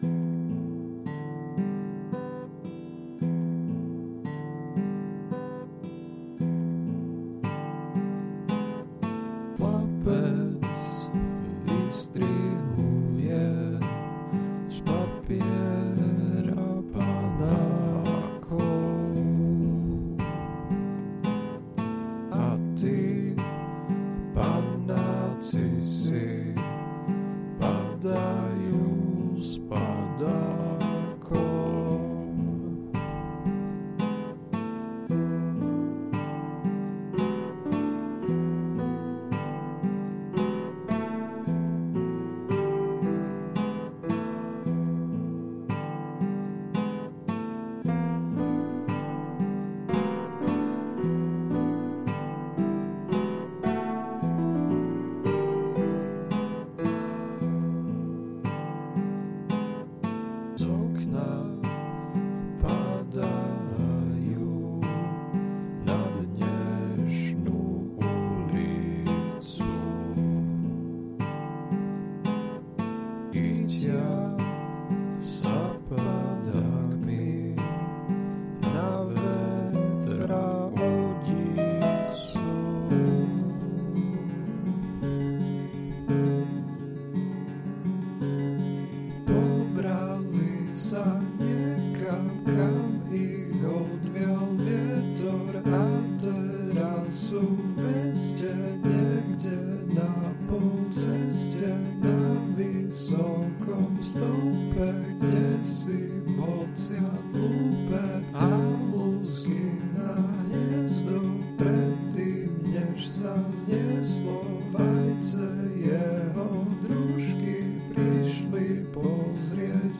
kategorie ostatní/písně